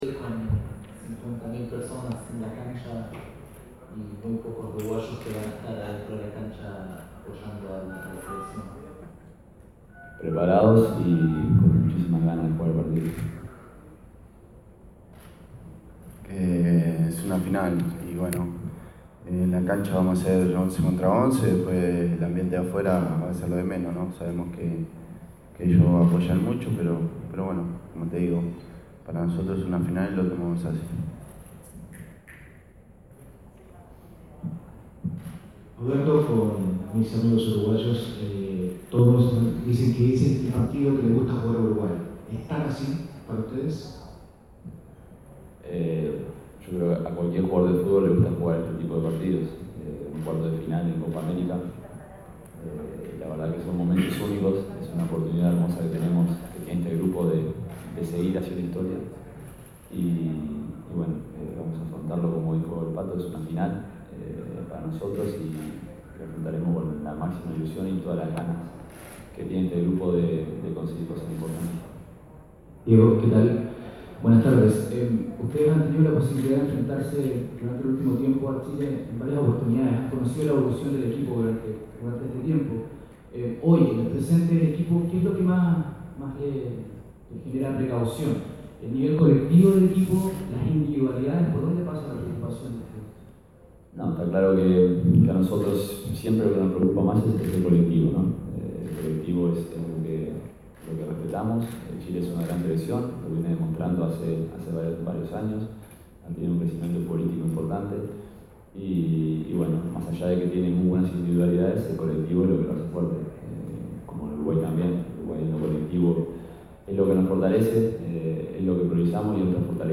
El capitán uruguayo habló en conferencia de prensa sobre el partido que se jugará el próximo miércoles, por los cuartos de final de la Copa América.